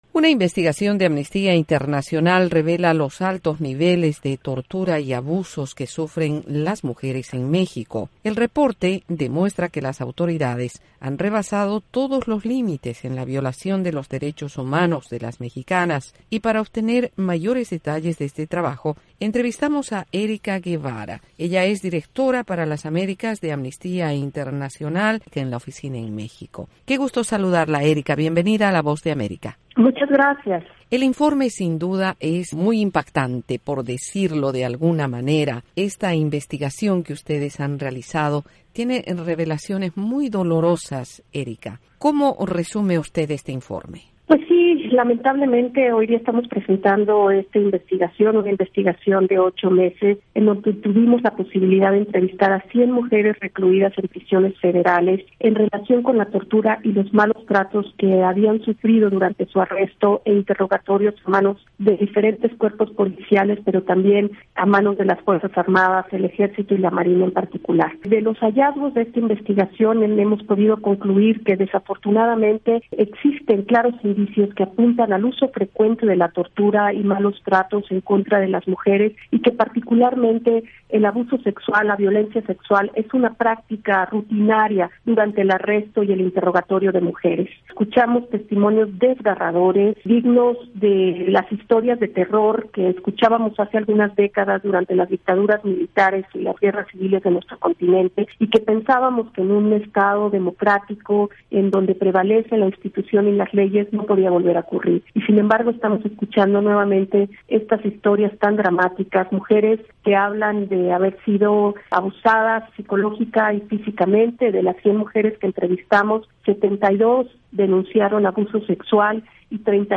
en entrevista con la Voz de América.